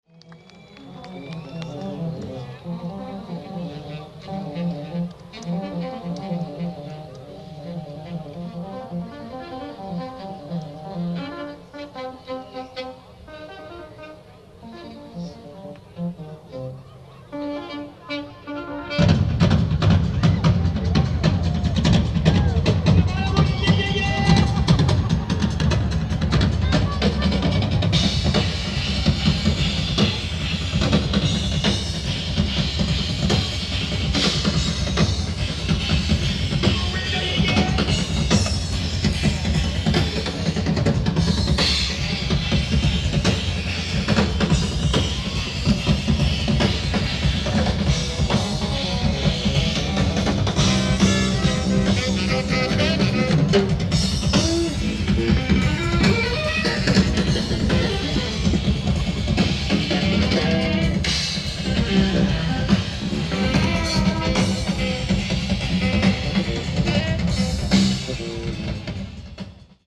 AUD> Nakamichi 350
---> iZotope RX-Advanced (noise reduction, spectral editing)
---> HarBal (EQ matching to try to fix some things)